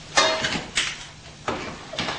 PLAY (再投稿)謎の金属音